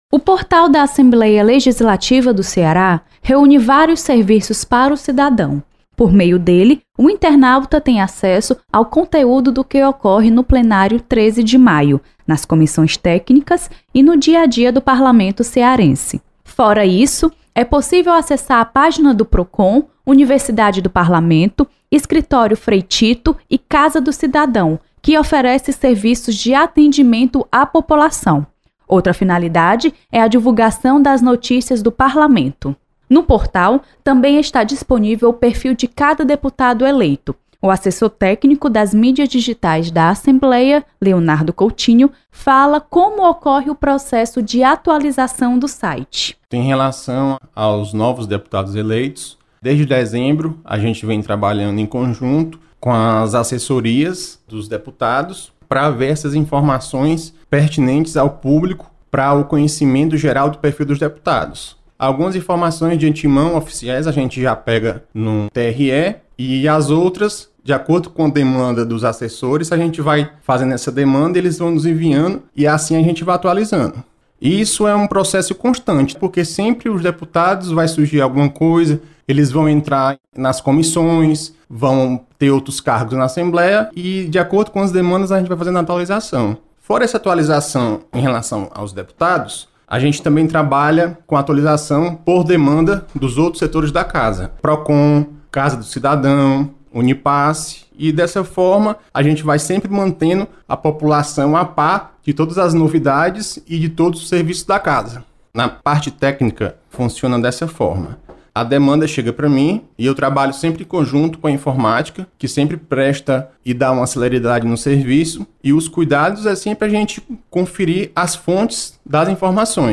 Portal da Assembleia é espaço para contato e prestação de serviços do Poder Legislativo com a sociedade. Repórter